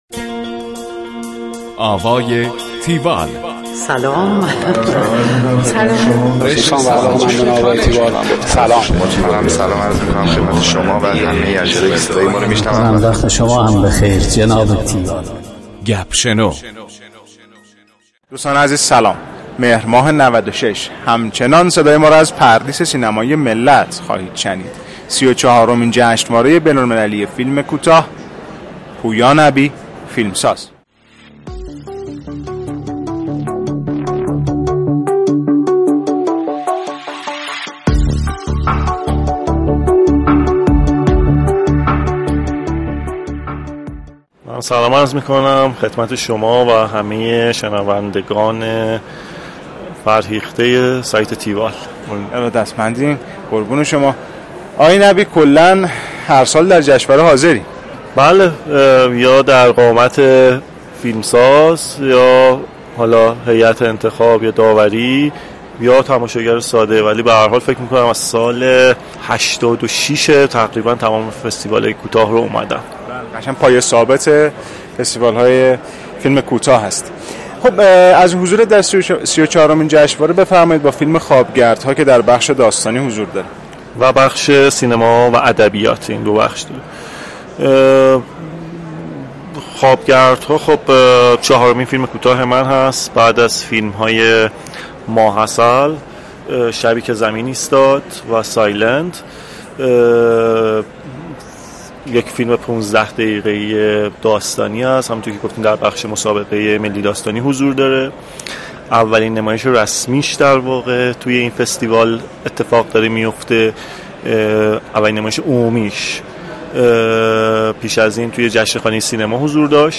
گفتگوی تیوال با